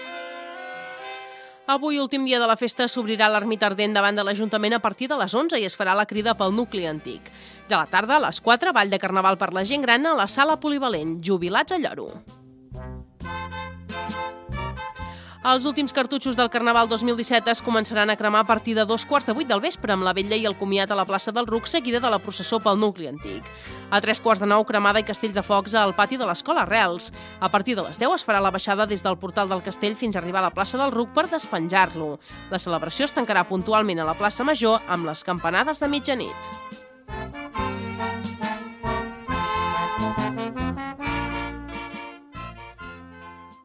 Gènere radiofònic Informatiu Data emissió 2017-03-01 Banda FM Localitat Solsona Comarca Solsonès Durada enregistrament 00:43 Notes Enregistrament extret del programa "Les Veus dels Pobles" de Ràdio Arrels.